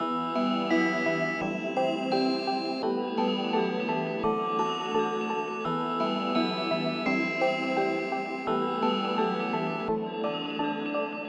描述：用我的手指弹出我的嘴。使用TASCAM DR05线性PCM记录仪记录。
Tag: 弹出 手指 持久性有机污染物 流行 扑通一声